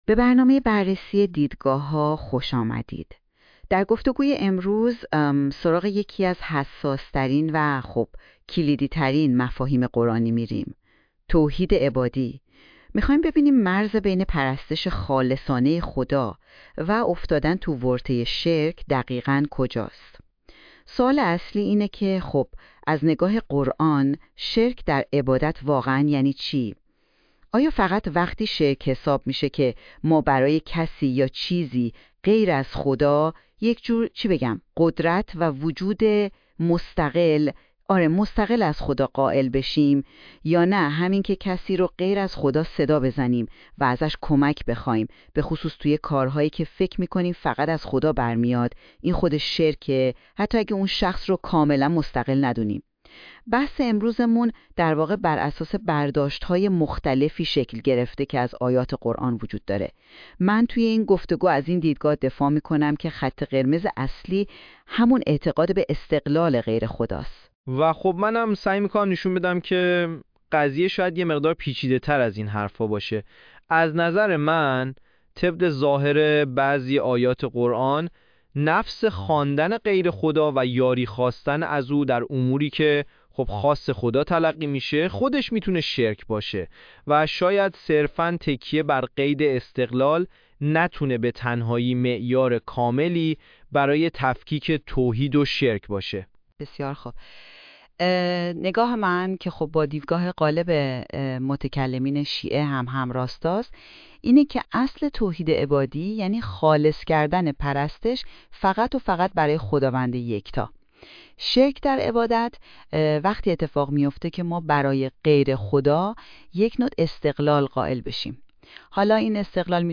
نمونه پادکست تولید شده با هوش مصنوعی: _توحید_عبادی_و_شرک_مرز_بین (1).mp3